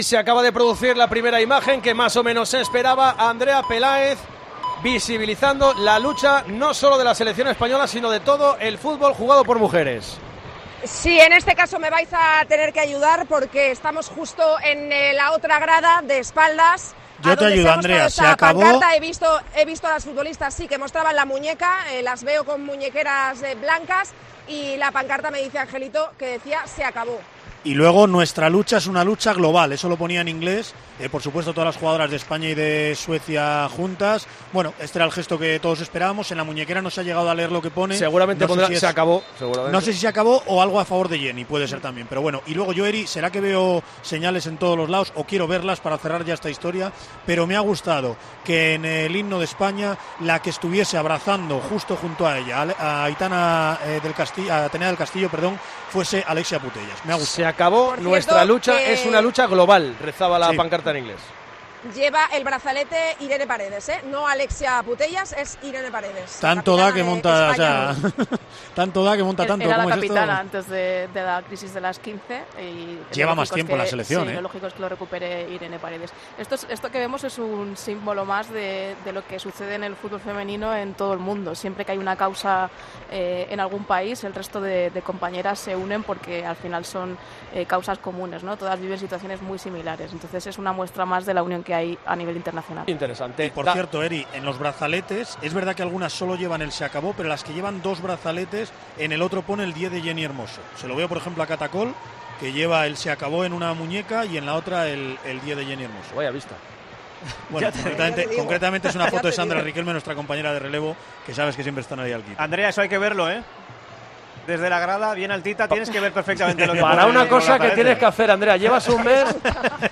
Unas muestras de apoyo a las que reaccionó en directo Tiempo de Juego en directo, teniendo en cuenta la importancia que tienen estos gestos internacionalmente.
Con Paco González, Manolo Lama y Juanma Castaño